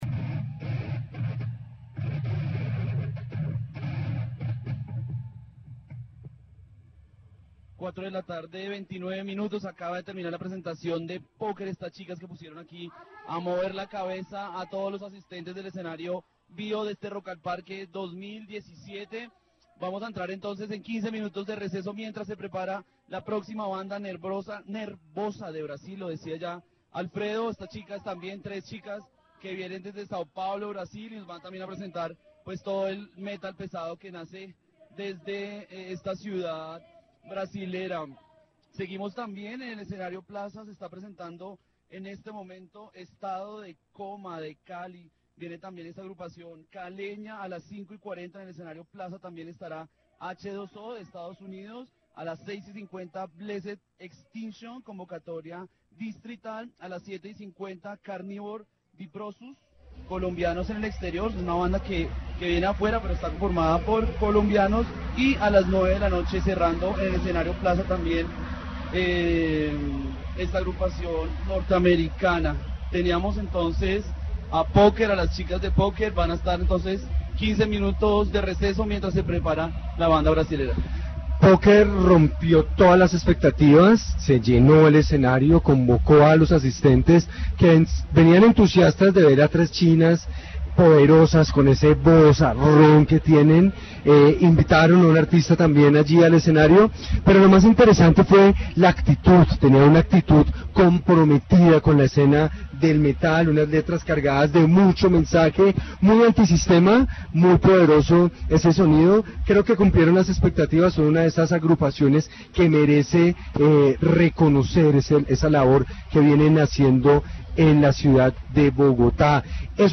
The radio report features the participation of the band Fénix from Cartagena at Rock in the Park 2017. The members share their impressions of performing in Bogotá, highlighting the challenge of breaking stigmas linked to metal music on the Caribbean coast and the positive reception from the capital's audience. They also comment on the music scene in Cartagena, their future projects at festivals such as Grita in Manizales, and upcoming shows in cities like Barranquilla and Medellín.